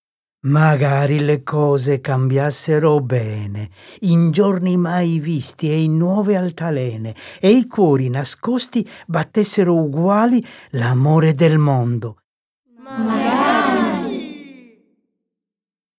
La sequenza completa e continua delle 23 quartine, coi loro cori a responsorio, è offerta in coda al libro con un QR-code.